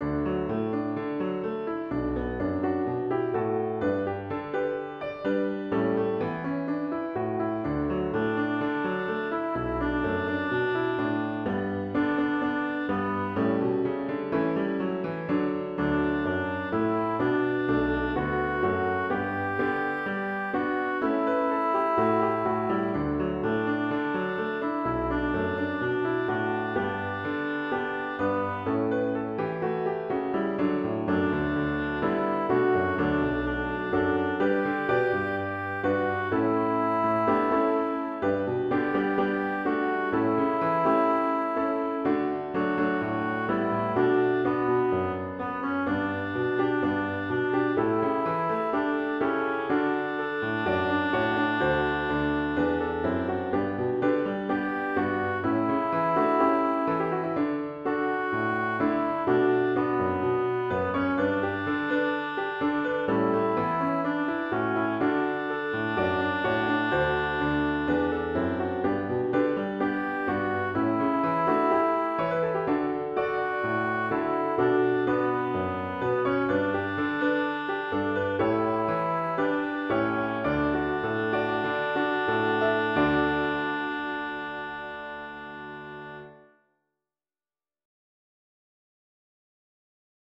with piano accompaniment
wbwc2027_melody_accomp.mp3